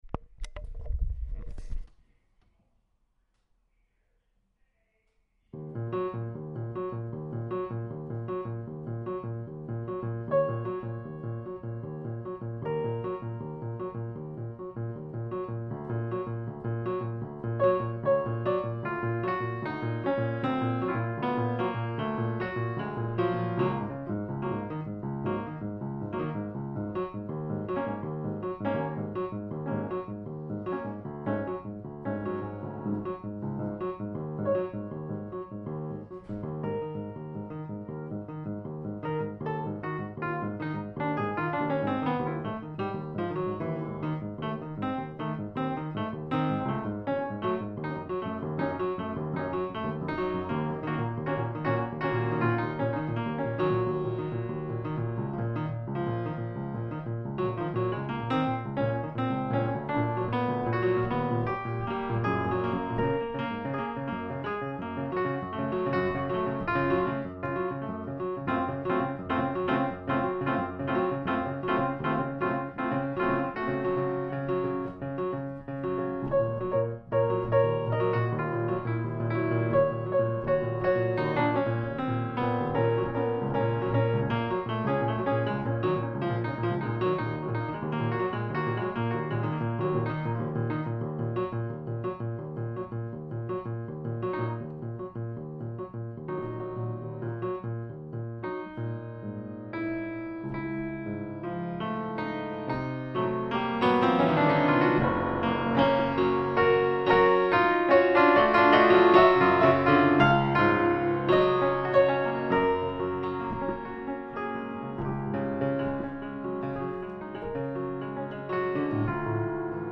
.. click here to hear reading fern hill